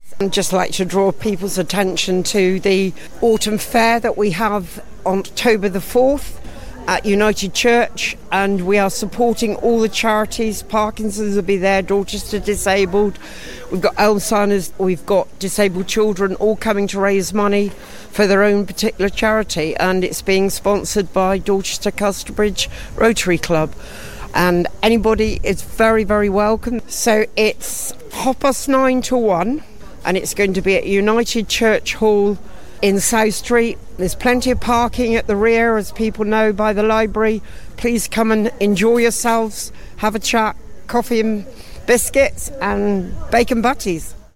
More interviews from the Mayor's fundraising event on 6th September - KeeP 106